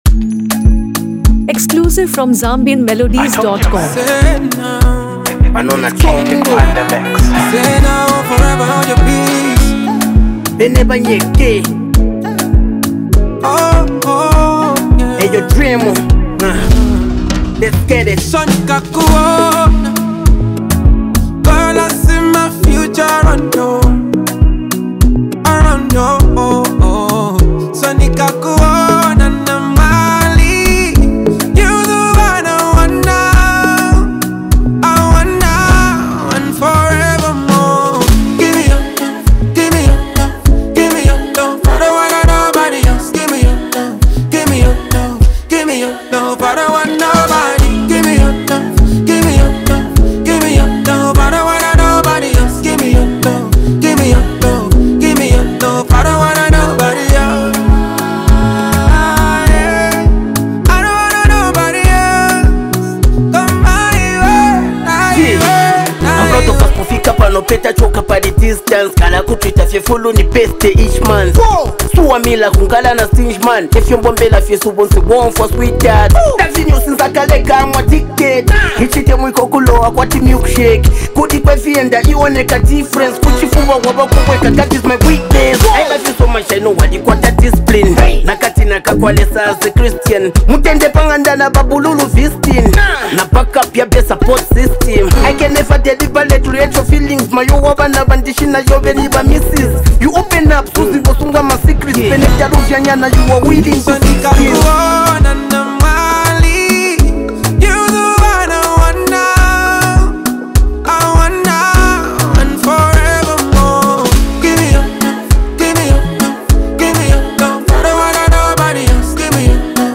blends Zambian hip-hop flair with Afro-soul melodies